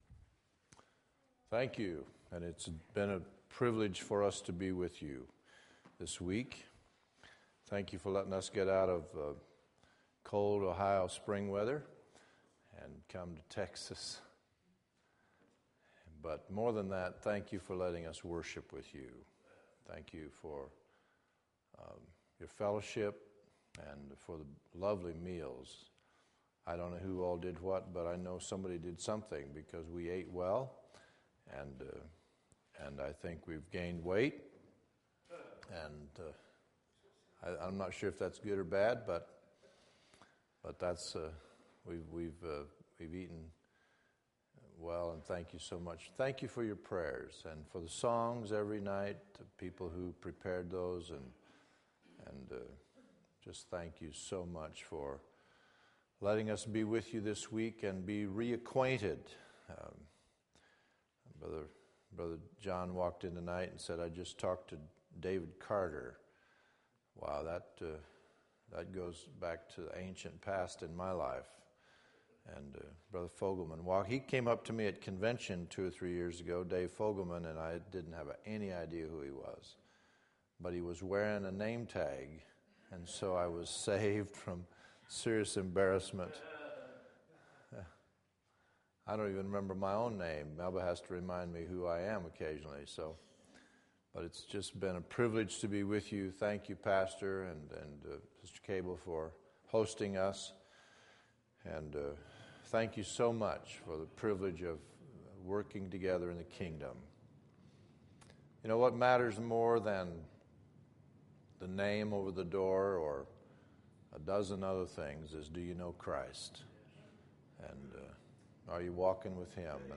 Spring Revival 2011